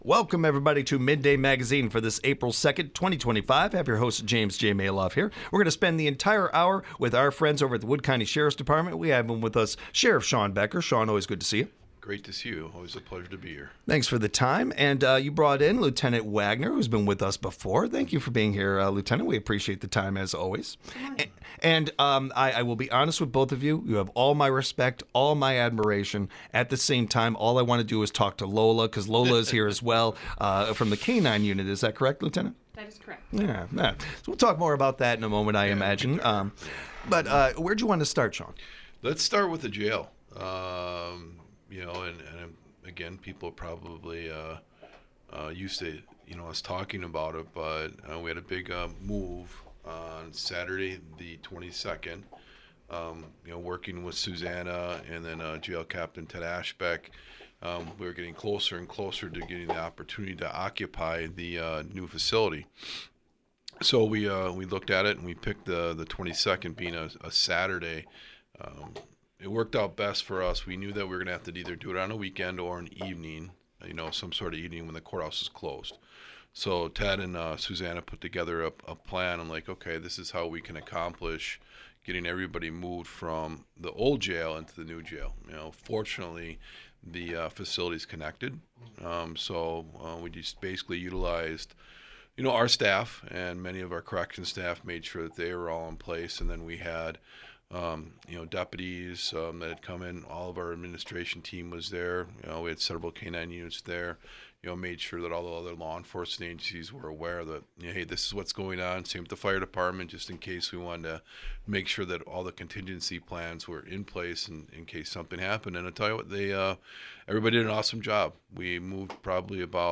This interview was recorded in cooperation with Wisconsin Rapids Community Media.